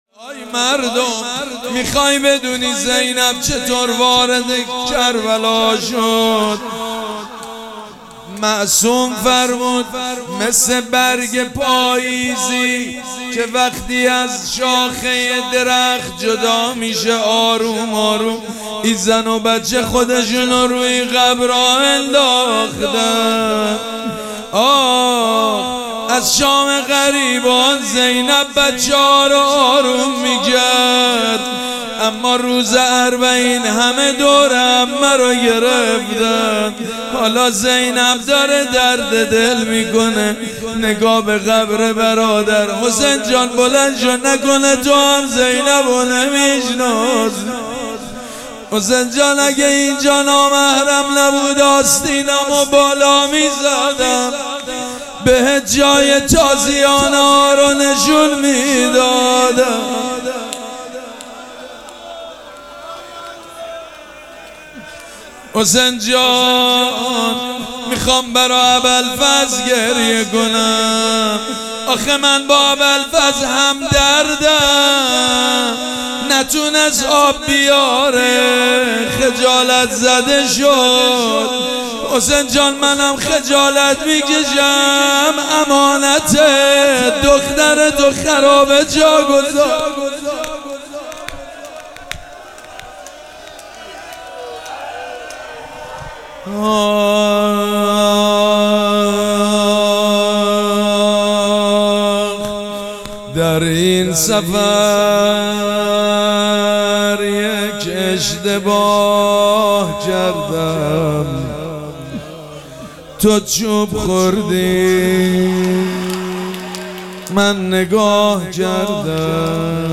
شب پنجم مراسم عزاداری اربعین حسینی ۱۴۴۷
روضه
مداح